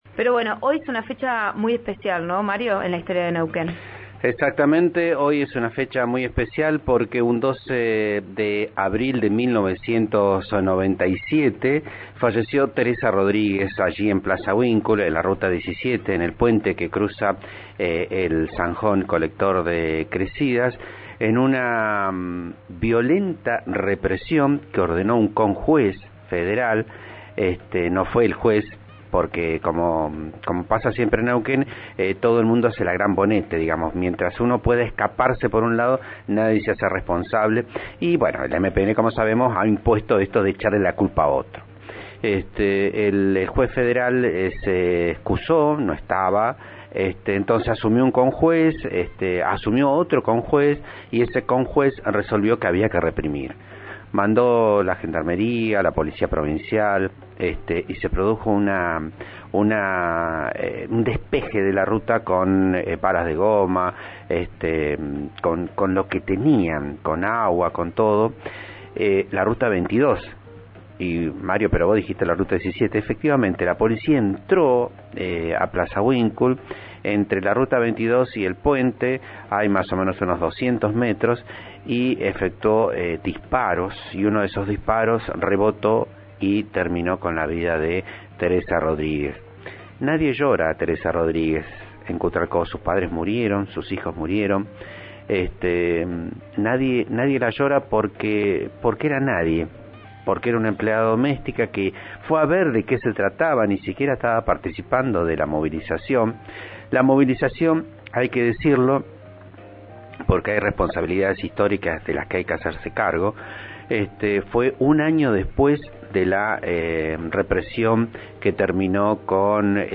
Análisis